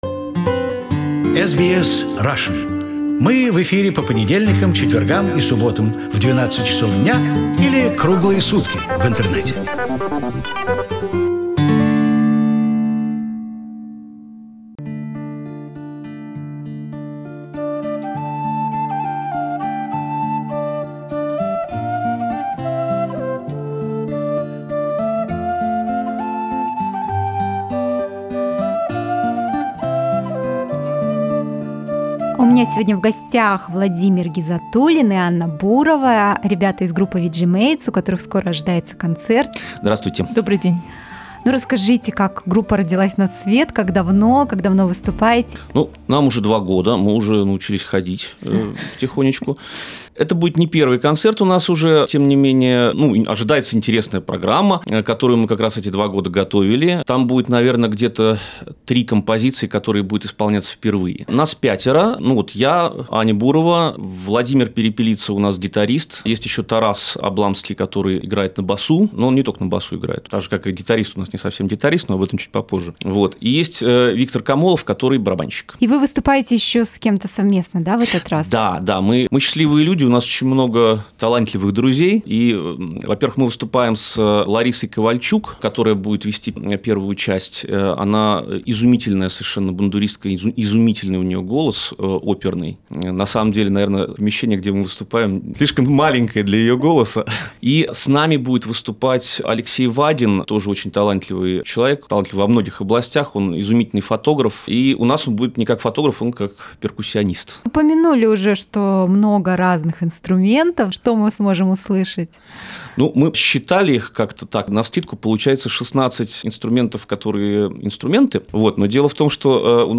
С собой, без сомнения, захватили свои интересные инструменты.